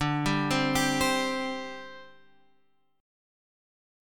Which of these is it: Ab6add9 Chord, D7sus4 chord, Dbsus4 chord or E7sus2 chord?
D7sus4 chord